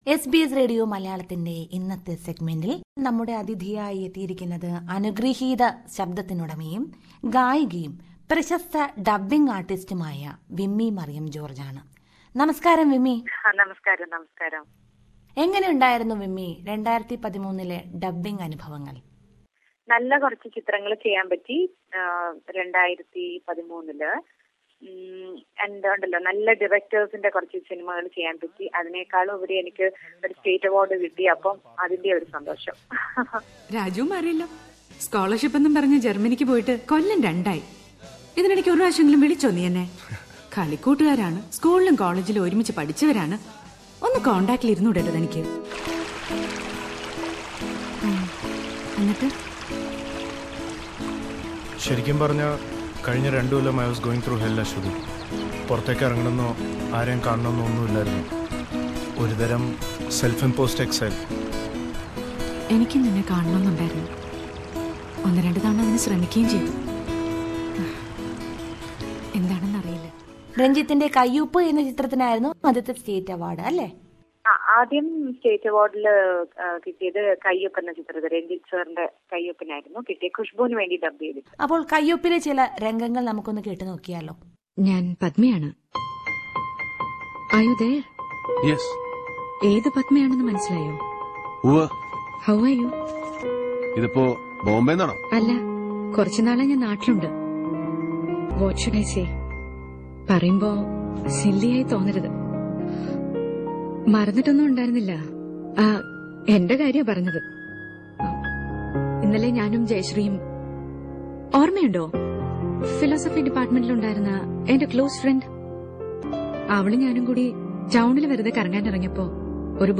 SBS Malayalam interviews celebrities and also those who excel in various fields. Unlike cine stars, movie directors and singers, here is an interview with a well known award winning dubbing artist, who contributes her sweet voice for movies and advertisements.